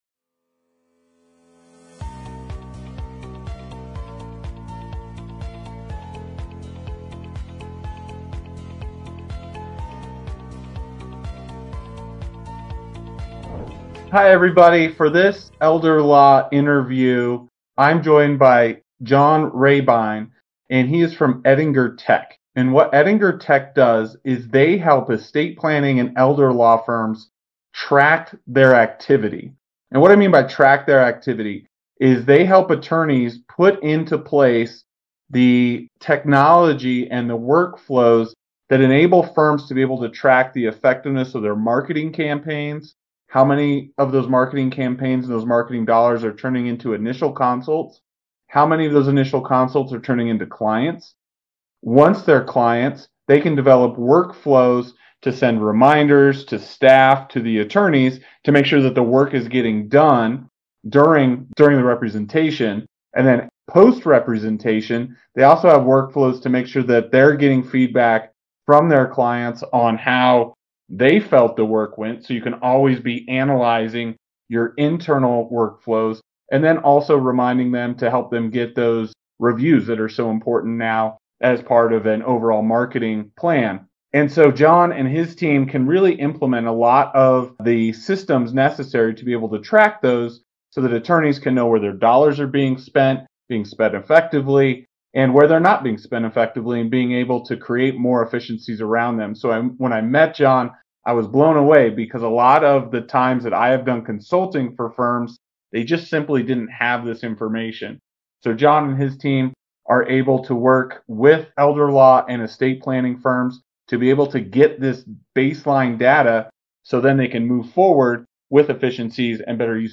In this Elder Law Interview